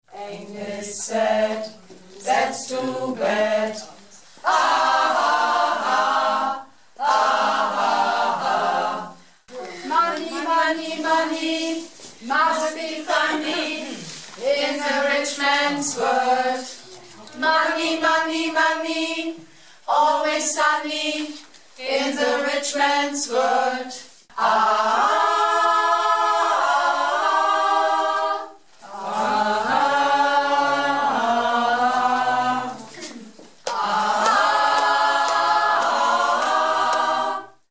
(Accapella-Teile)